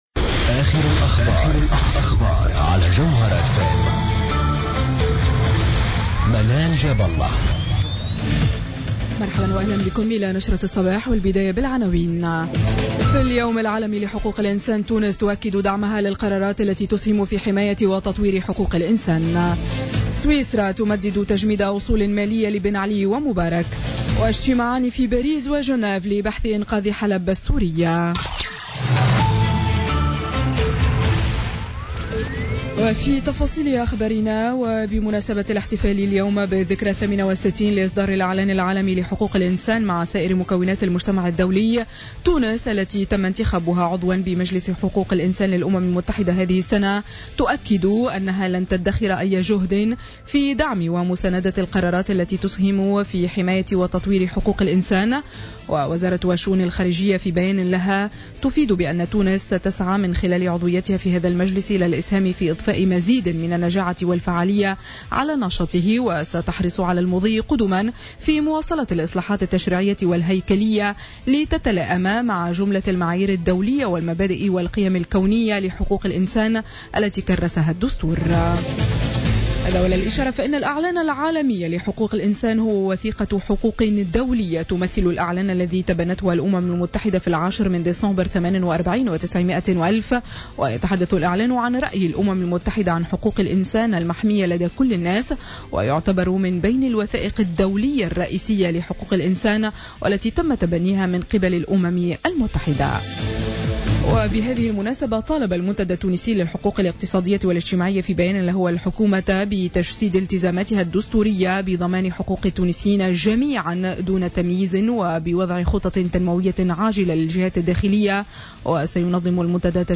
Journal Info 07h00 du samedi 10 Décembre 2016